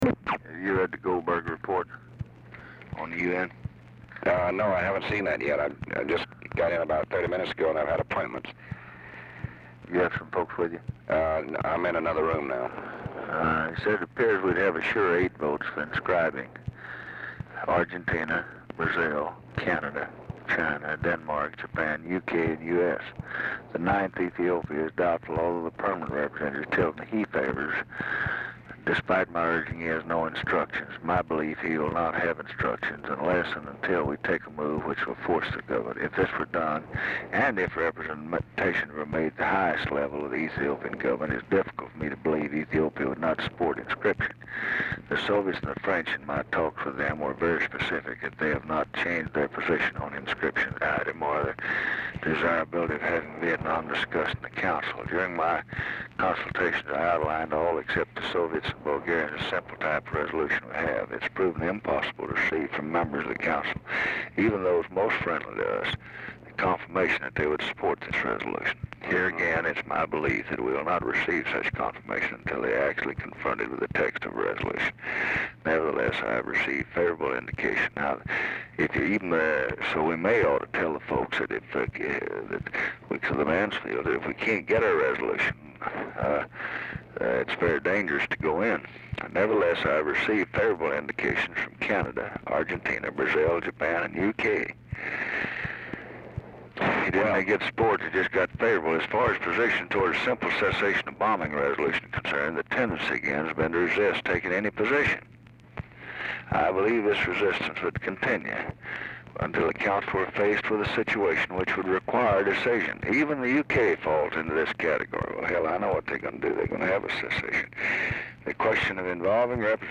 Mansion, White House, Washington, DC
Telephone conversation
Dictation belt